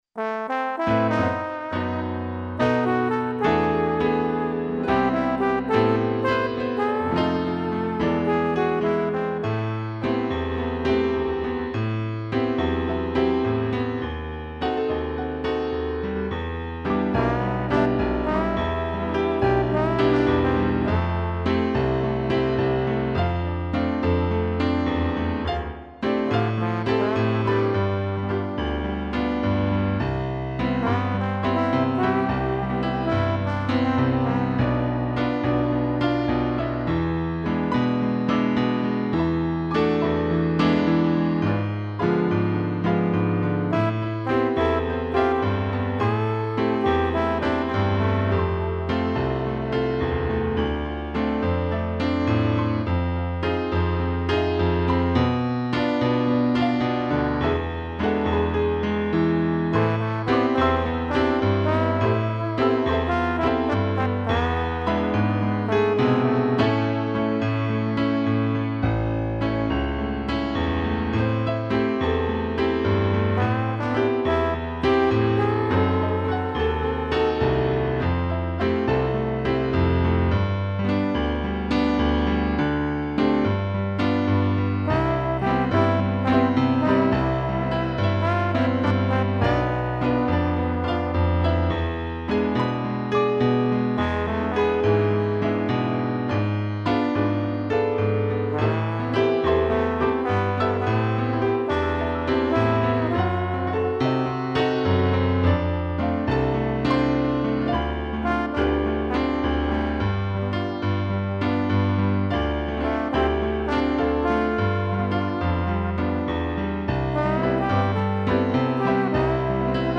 2 pianos e trombone
(instrumental)